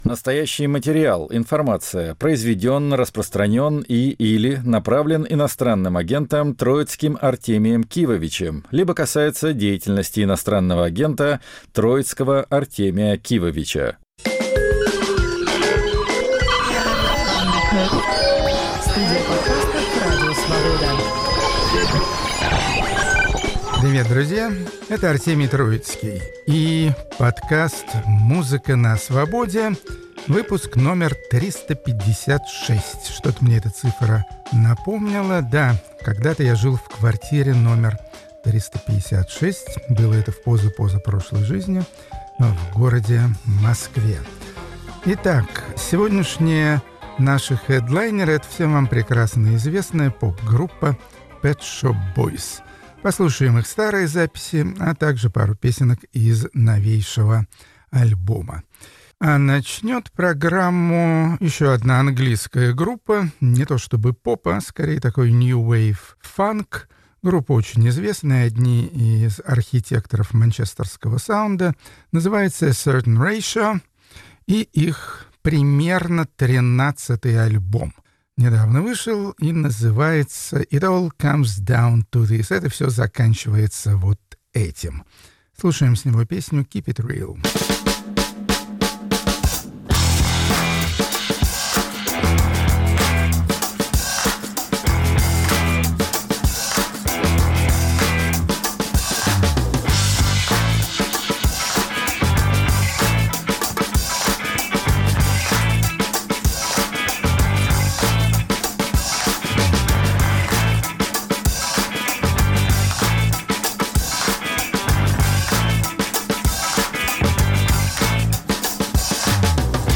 Рок-журналист Артемий Троицкий делится в своём подкасте новой качественной музыкой разных стилей и направлений. Немного России и много экзотики.